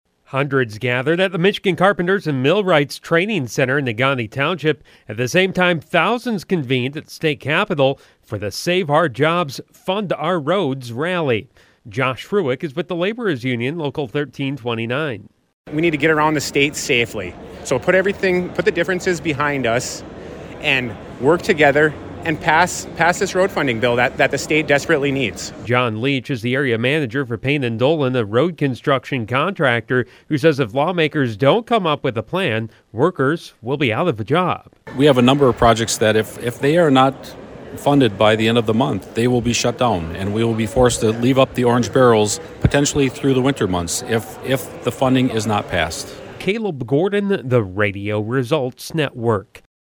REPORT ON NEGAUNEE RALLY